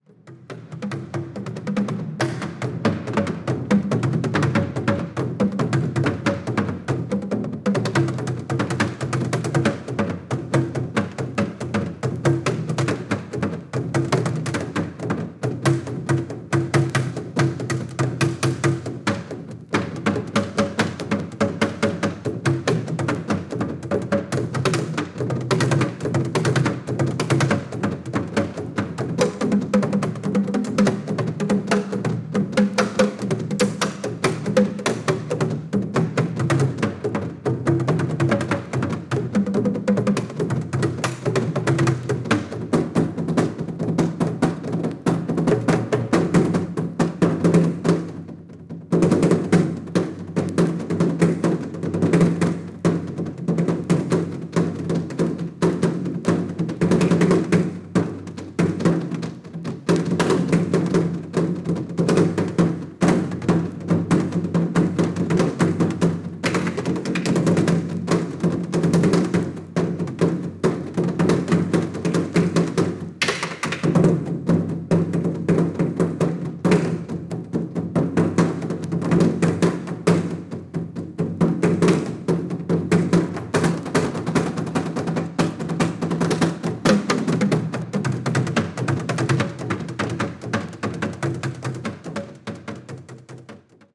和太鼓＆津軽三味線です。
高音質盤！！！